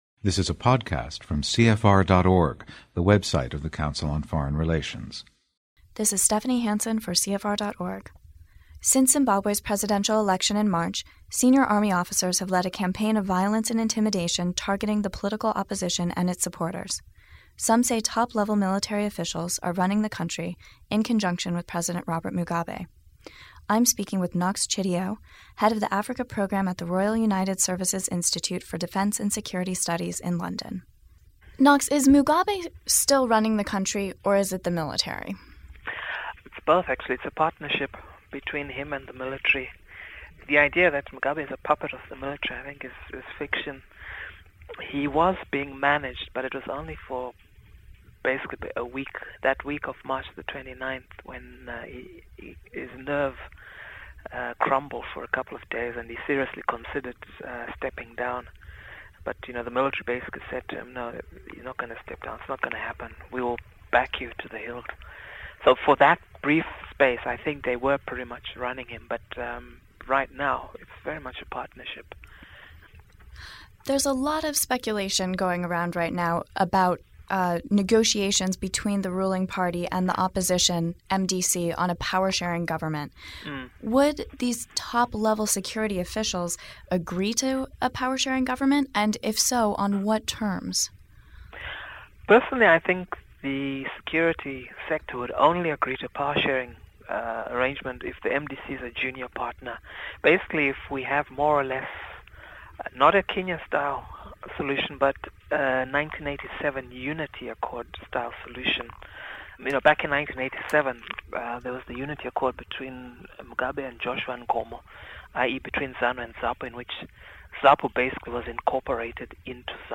Access this interview on the Council on Foreign Relations website